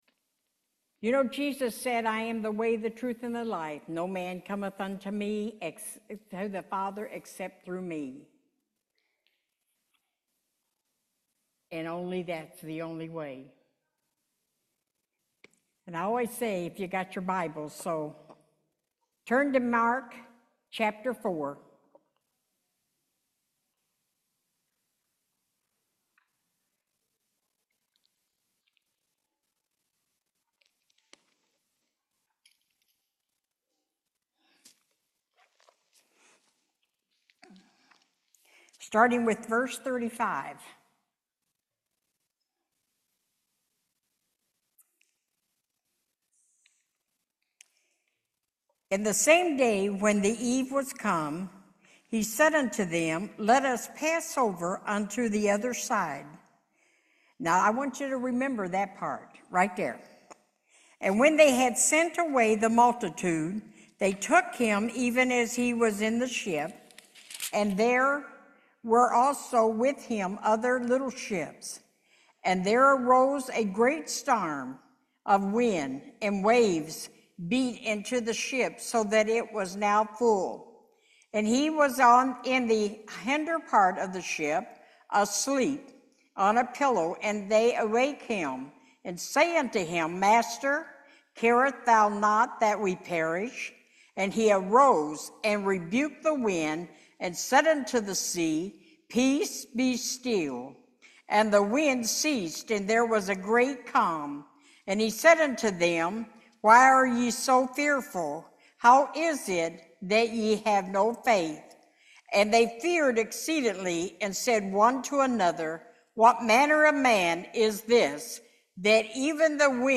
Mark 4:35-41 Service Type: Main Service If He brought you to it